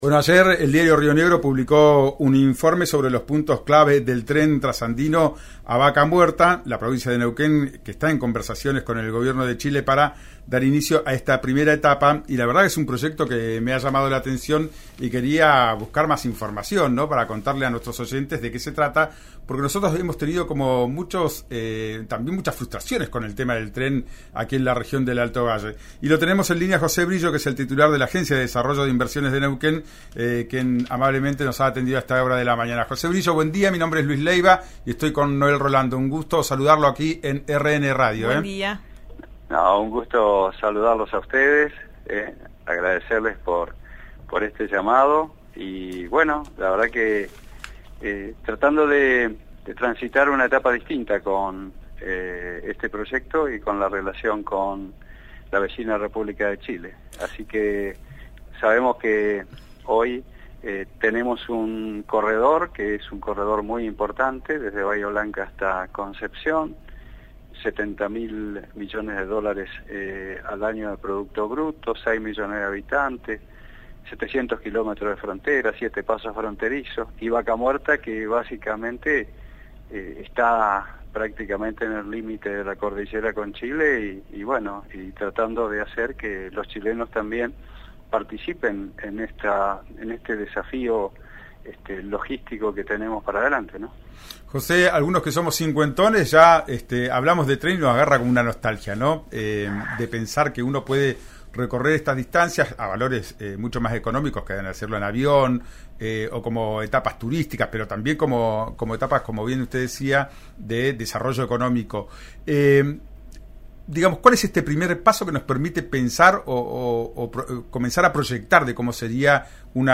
Escuchá al presidente de la Agencia de Inversiones de Neuquén José Brillo en «Ya es tiempo» por RÍO NEGRO RADIO: